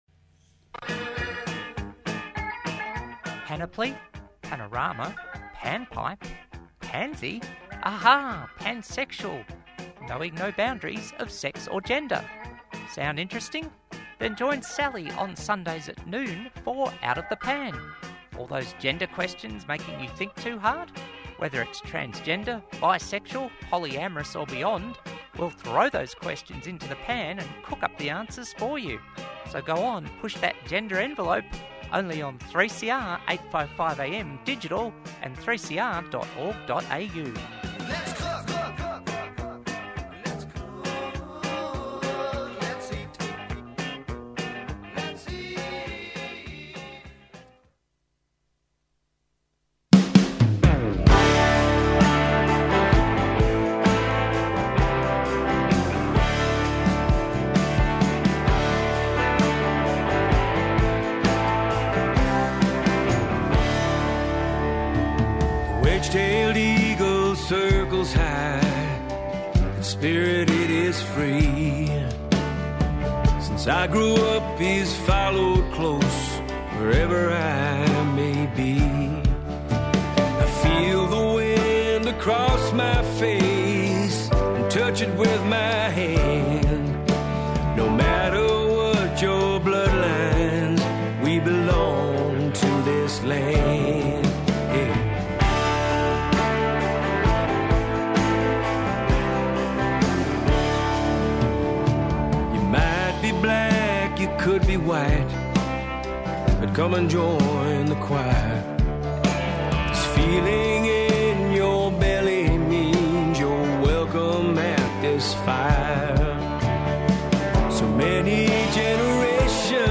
News roundup and commentary